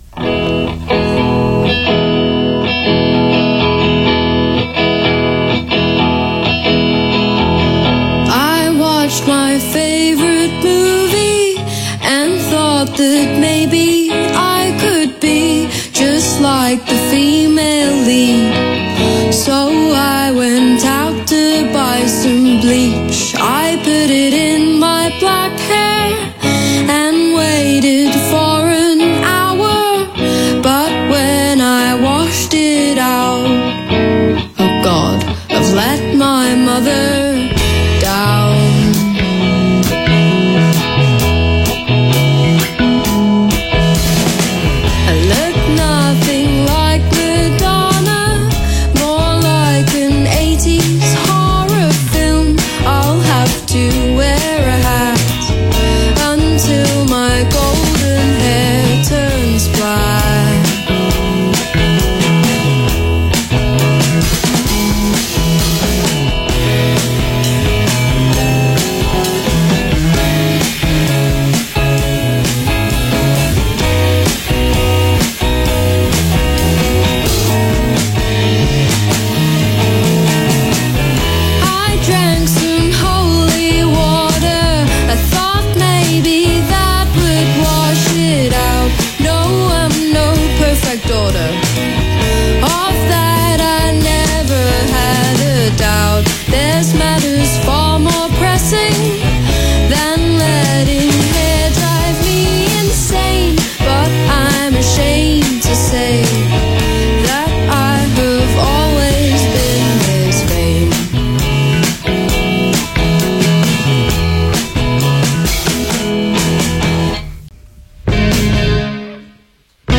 The result is refreshing, upbeat and engaging.
indie trio
jazz, classic soul, and ’90s dream pop as primary influences